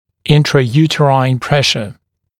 [ˌɪntrə’juːtəraɪn ‘preʃə][ˌинтрэ’йу:тэрайн ‘прэшэ]внутриматочное давление